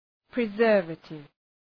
Προφορά
{prı’zɜ:rvətıv} (Επίθετο) ● διατηρητικός (Ουσιαστικό) ● συντηρητικό